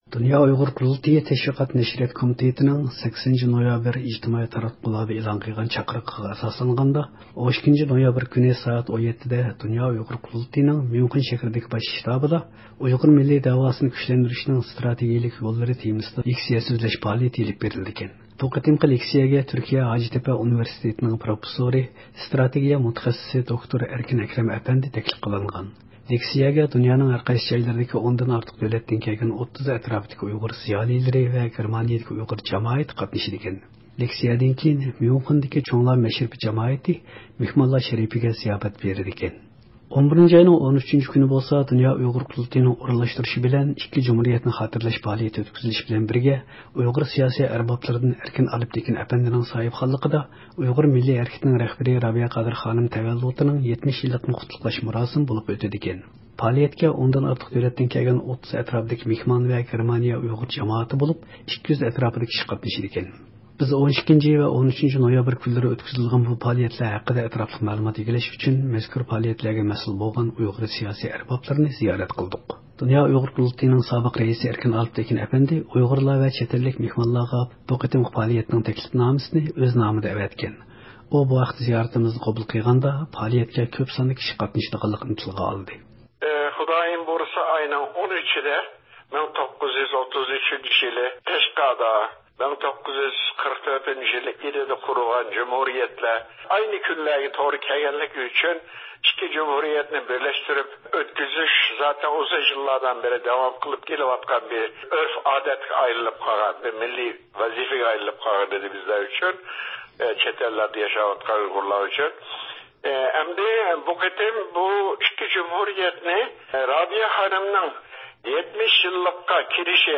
بىز 12 ۋە 13-نويابىر كۈنلىرى ئۆتكۈزۈلىدىغان بۇ پائالىيەتلەر ھەققىدە ئەتراپلىق مەلۇمات ئىگىلەش ئۈچۈن، مەزكۇر پائالىيەتلەرگە مەسئۇل بولغان ئۇيغۇر سىياسىي ئەربابلىرىنى زىيارەت قىلدۇق.